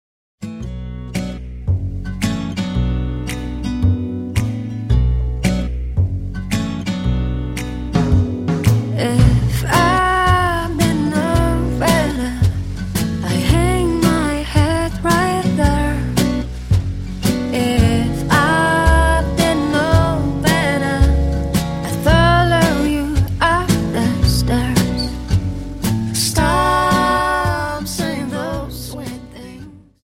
Dance: Slowfox
(Slowfox 28) Album